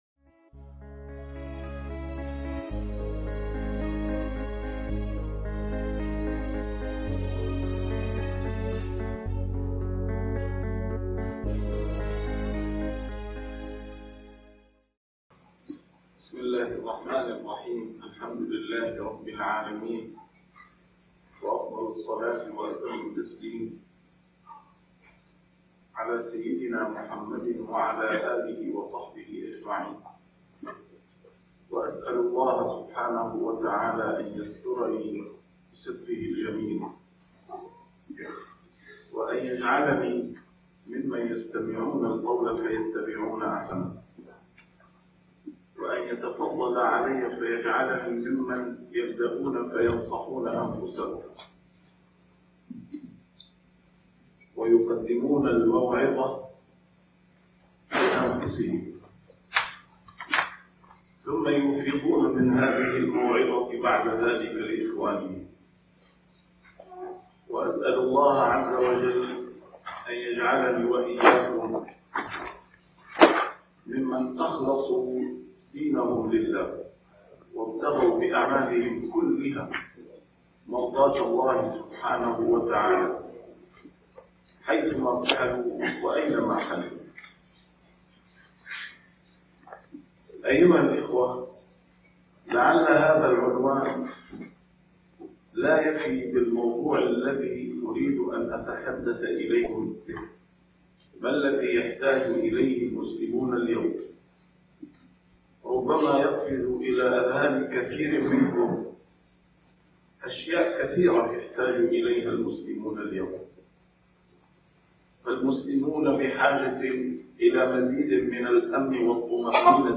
A MARTYR SCHOLAR: IMAM MUHAMMAD SAEED RAMADAN AL-BOUTI - الدروس العلمية - محاضرات متفرقة في مناسبات مختلفة - الازدواجية التي يعاني منها المسلمون اليوم | محاضرة في أمريكا